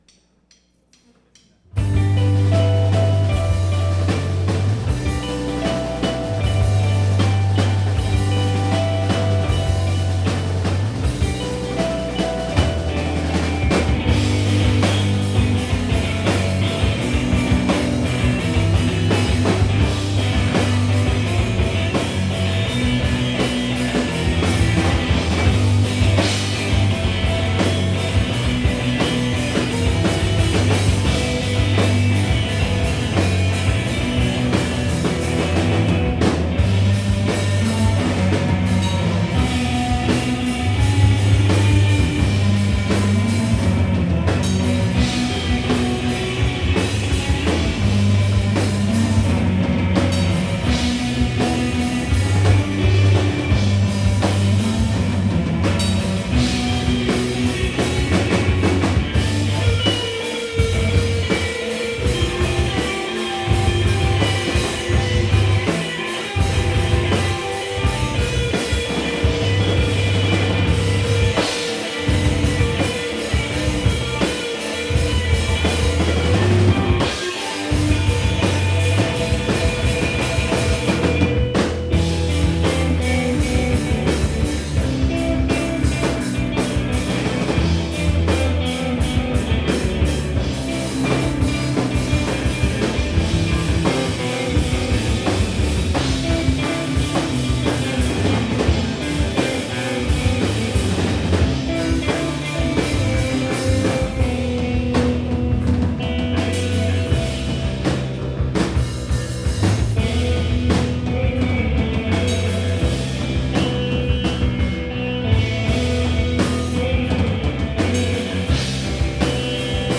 2006-10-15 Nectar Lounge – Seattle, WA